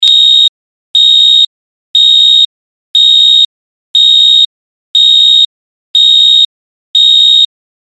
دانلود صدای ساعت 28 از ساعد نیوز با لینک مستقیم و کیفیت بالا
جلوه های صوتی